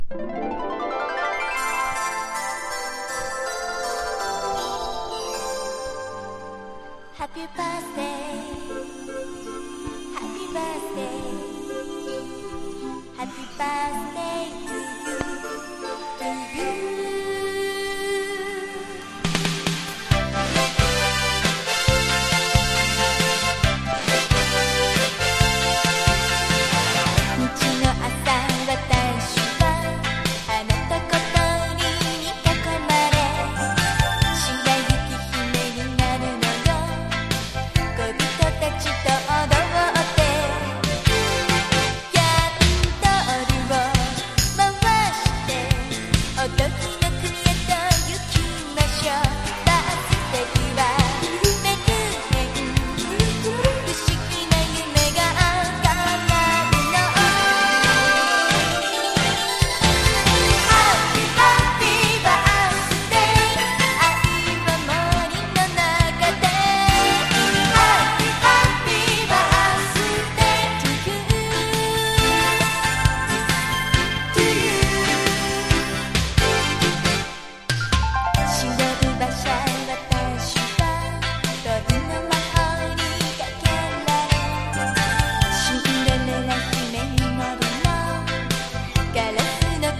とにかくキャッチーで元気な1曲。
POP
ポピュラー# 70-80’S アイドル